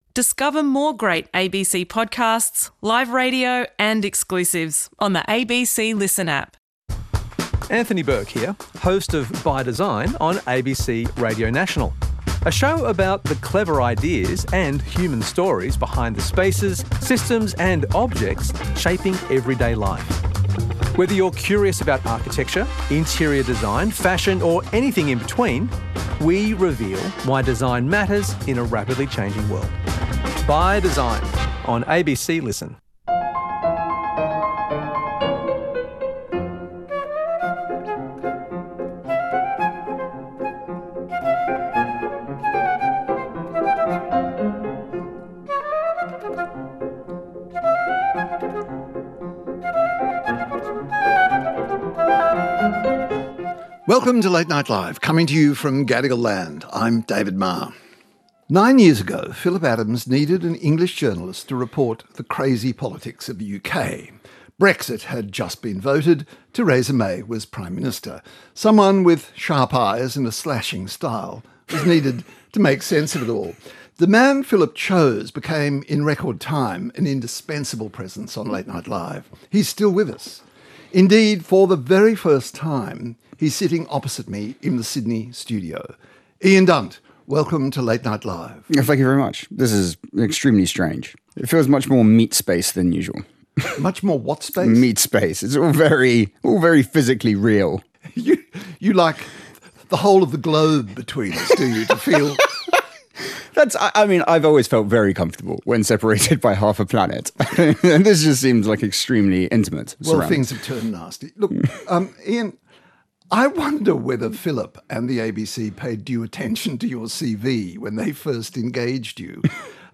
For the first time, Late Night Live UK political commentator Ian Dunt, meets David Marr in person. In this special hour-long conversation, Dunt explores the parallels and distinctions between political developments in the United Kingdom and Australia, analyses the strains in relations between the UK and the US over the Iran conflict, and examines the recent Greens by-election win, in a long-held UK Labour seat.